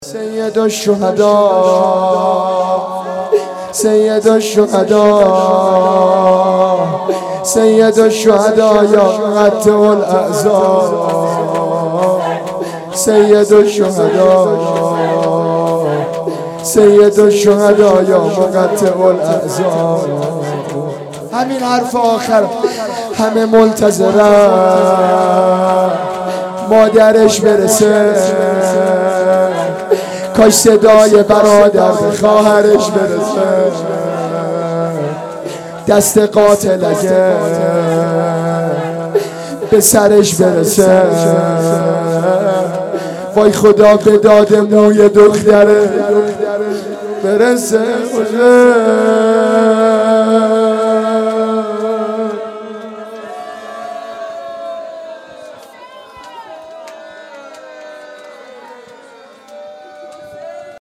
سیدالشهدا یا مقطع الاعضا _ شور قتلگاه
اقامه عزای رحلت پیامبر اکرم و شهادت امام حسن مجتبی علیه السلام